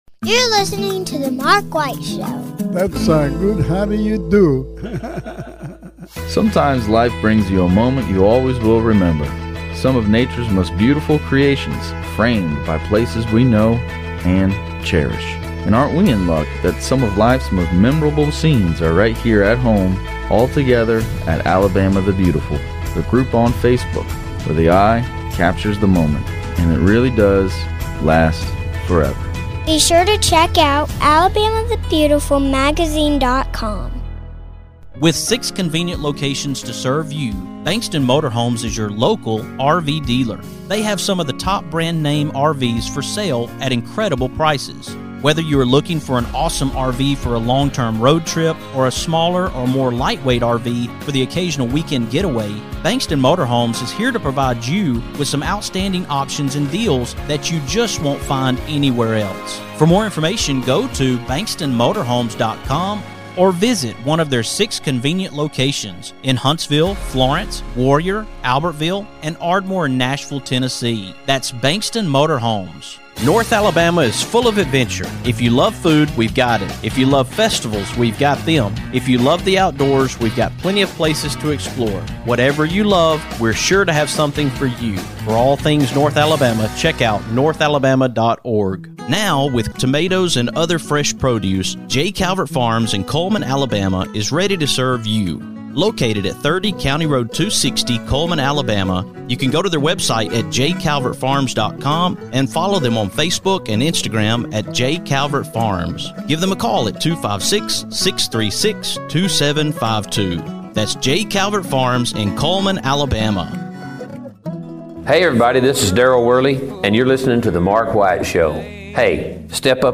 As advertised, Jon Walmsley is joining me tonight from the UK!